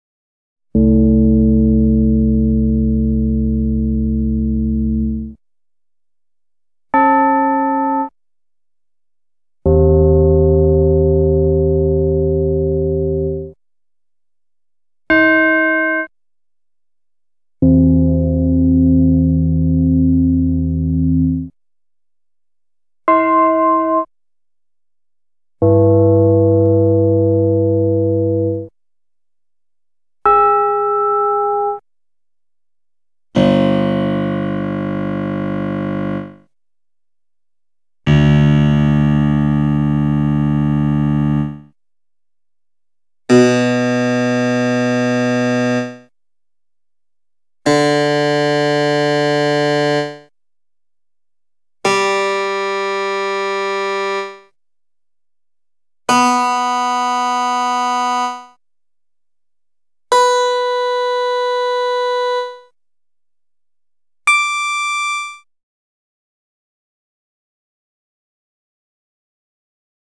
ChimesA2A2.wav